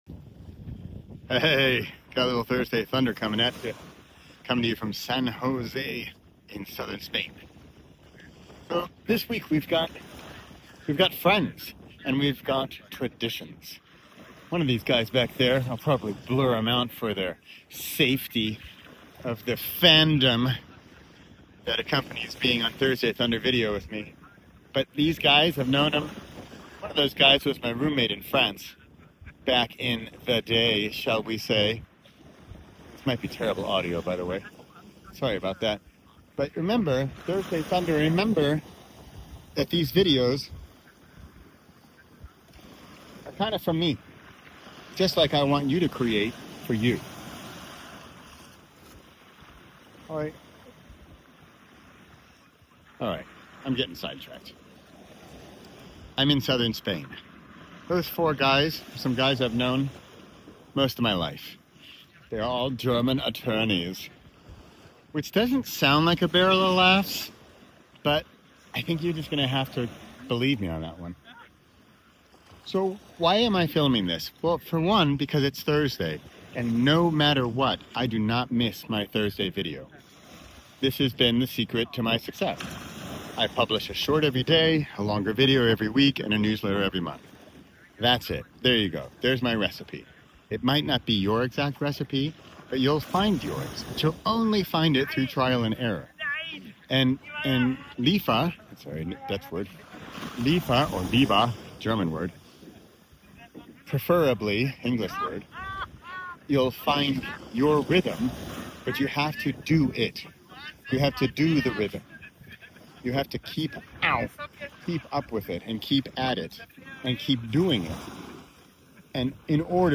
re507: Why You Can’t Stay Consistent (And What Actually Works) from San Jose, Spain